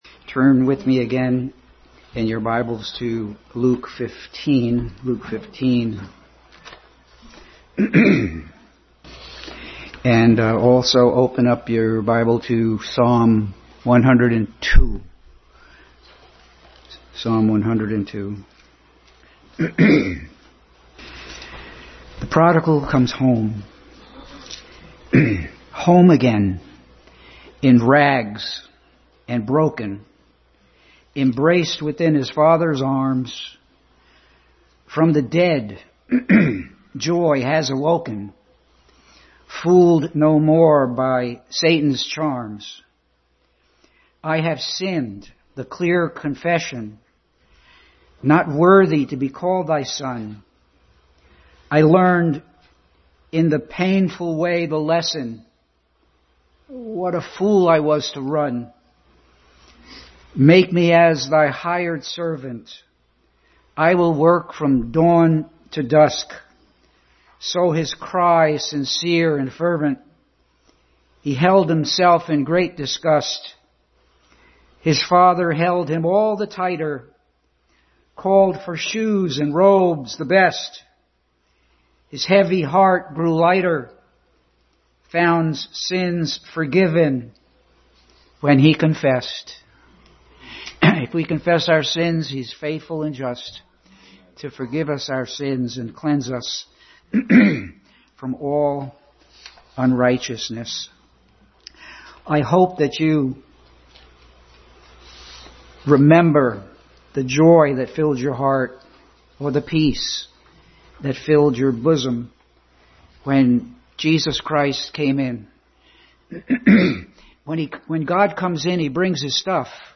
Passage: Luke 15:1-32, Psalm 102 Service Type: Family Bible Hour